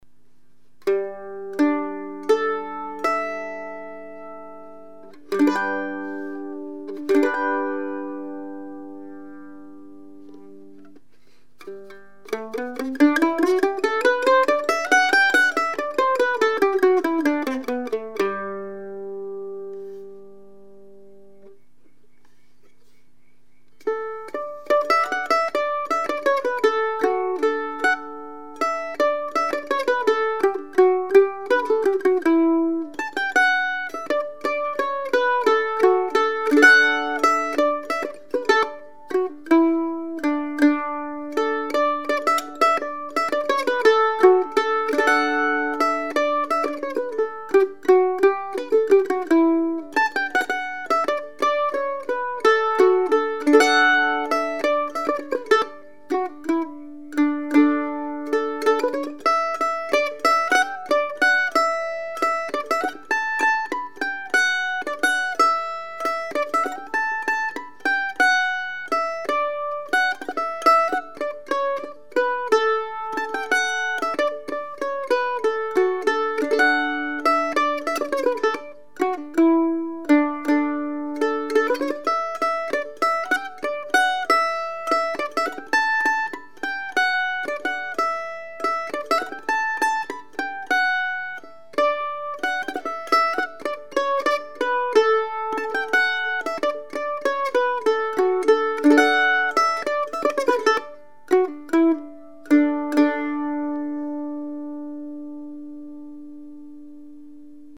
2 Point A5 Mandolin #342  $5200 (includes case)
The sound is gorgeous.  Sweet, responsive and very evenly balanced, and it does like the Thomastic strings.  Beautiful sound when played soft, but can really push out the volume when hit hard.